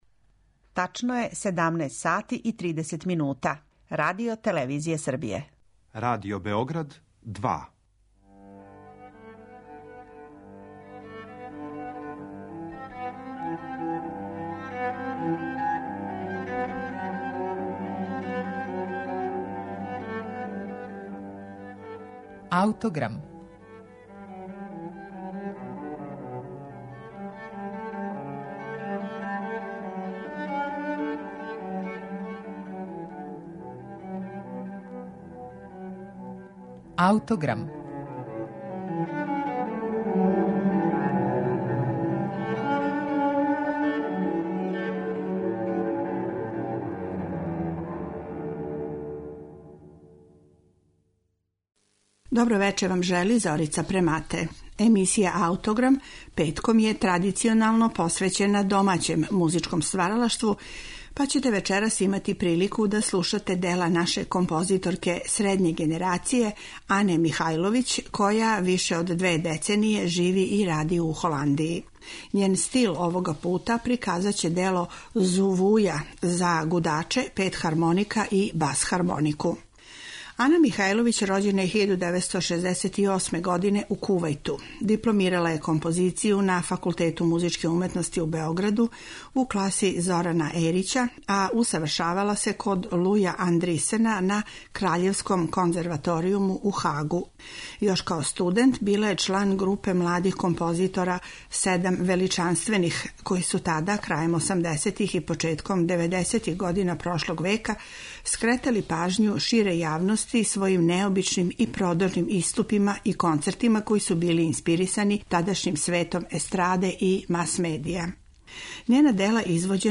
написано 2012. године за гудаче и шест хармоника.